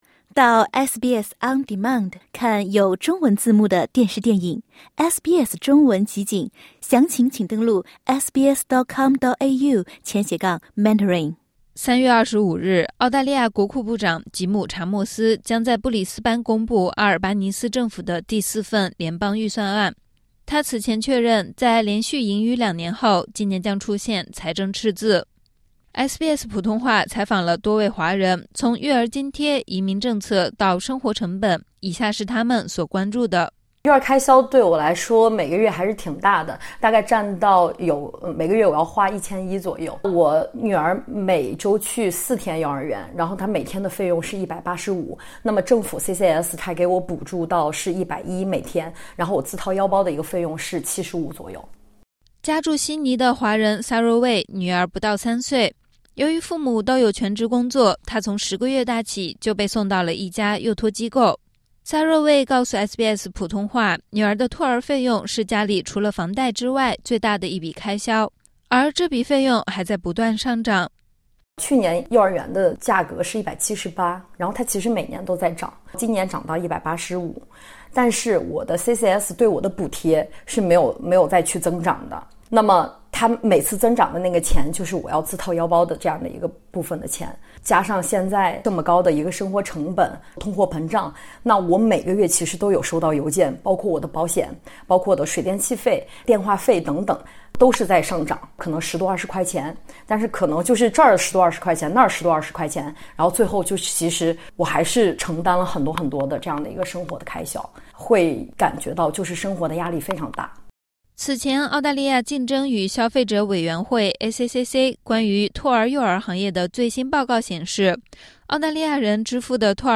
2025-26联邦预算案将于本周二（3月25日）公布，SBS 普通话采访了两名华人居民和一位移民行业从业者，他们分享了对本次预算案中育儿补贴、移民政策和生活成本的期望。